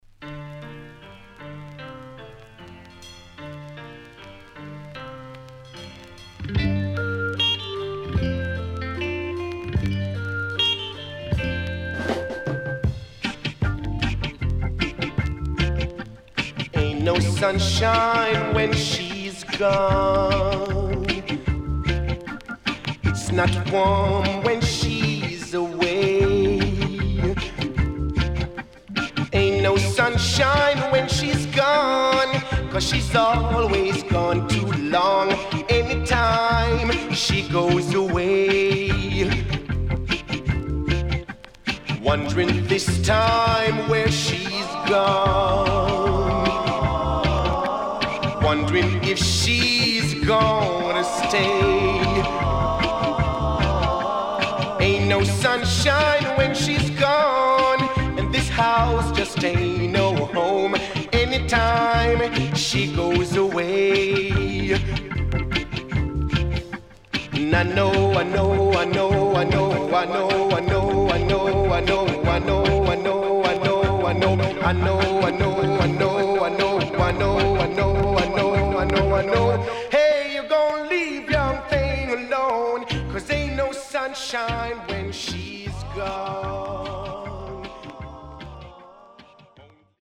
HOME > Back Order [VINTAGE LP]  >  EARLY REGGAE
SIDE A:所々チリノイズ、プチノイズ入ります。